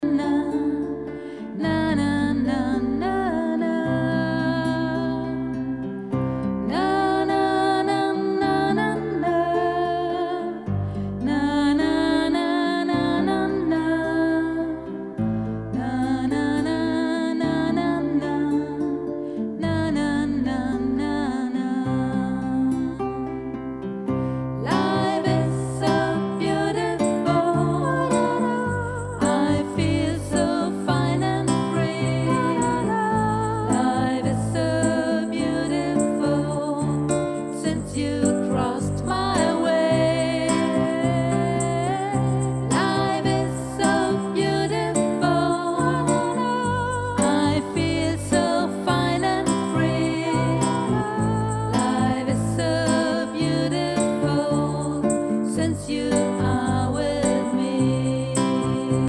melodic
relaxing
Genre: Healing Songs